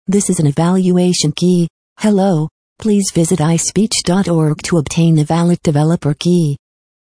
here to generate and download a MP3 text-to-speech conversion.